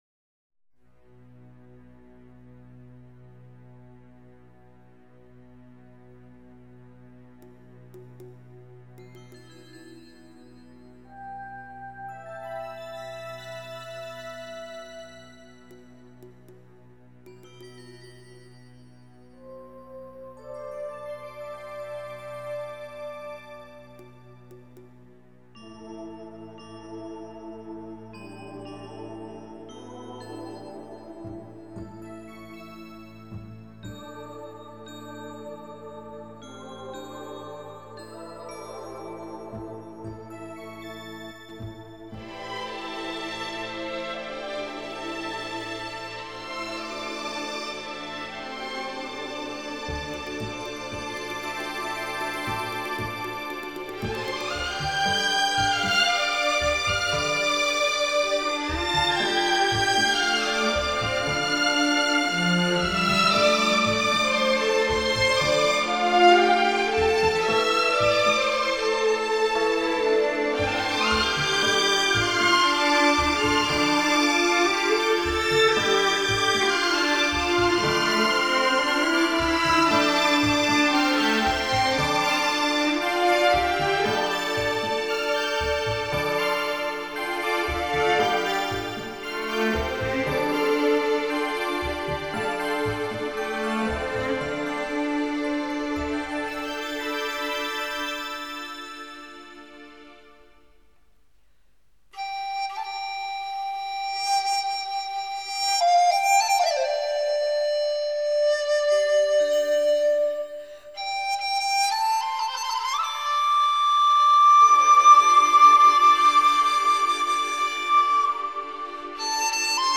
本辑是青海民歌改编的轻音乐。中国民族乐器为主奏，辅以西洋管弦乐器及电声乐器。